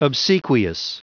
Prononciation du mot obsequious en anglais (fichier audio)
Prononciation du mot : obsequious
obsequious.wav